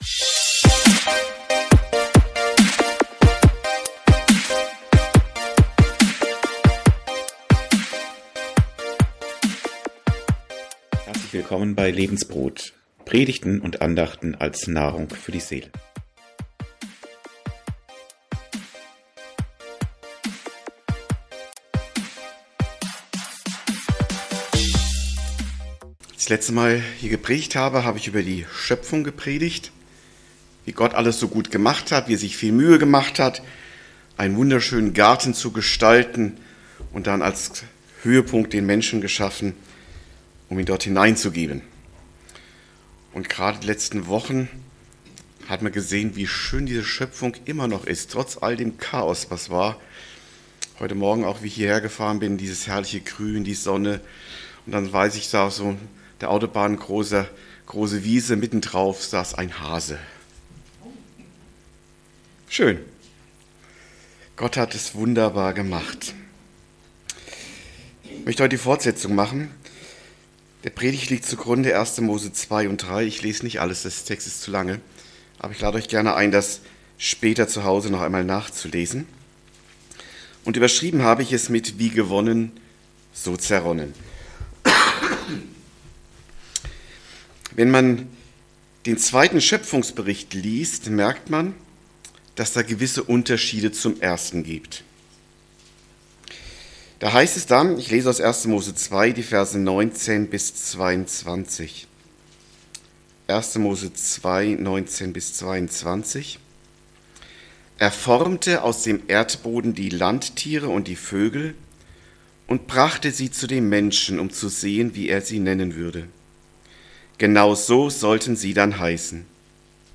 Predigt
1. Mose 2-3: Schöpfungsbereicht, Sündenfall und Gott als 1. Modedesigner -> neue, qualitativ bessere Aufnahme vom 12.5.24 Folge direkt herunterladen